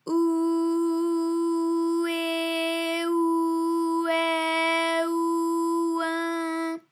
ALYS-DB-001-FRA - First, previously private, UTAU French vocal library of ALYS
ou_ou_eh_ou_ai_ou_oin.wav